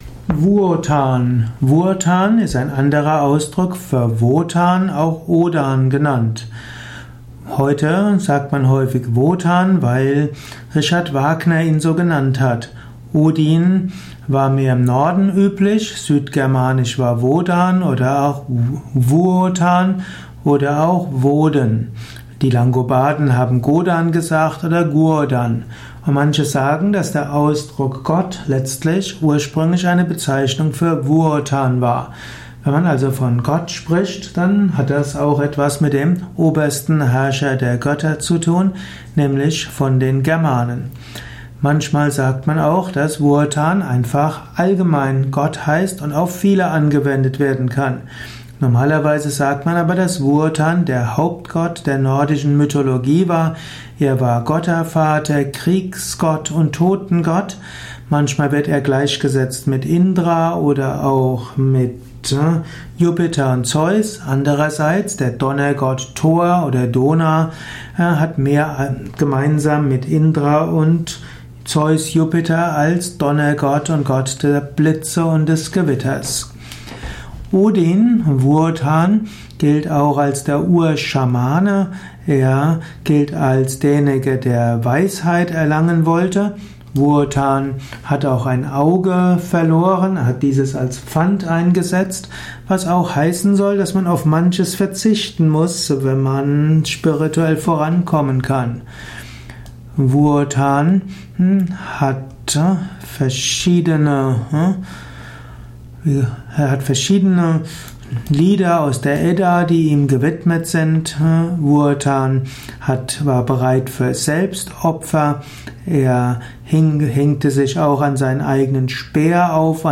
Informationen über die Bedeutung von Wuotan in der germanischen Mythologie, im germanischen Götterhimmel. Welche Bedeutung hat Gott Wuotan für die persönliche Entwicklung, den Lebensweg eines Aspiranten, einer Aspirantin? Dies ist die Tonspur eines Videos, zu finden im Yoga Wiki.